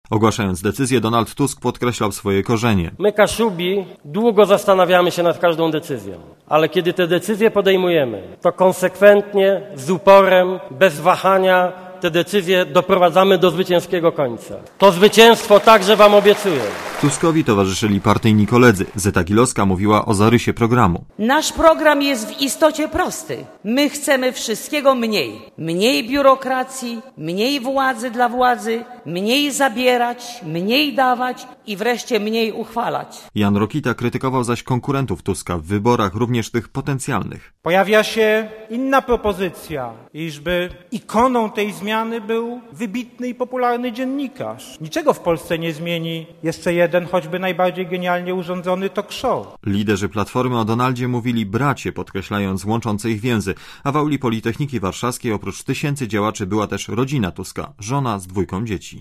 W Konwencji zorganizowanej w auli Politechniki Warszawskiej wzięło udział się ponad trzy tysiące działaczy PO i ich rodzin.